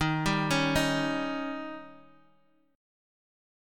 Eb7 chord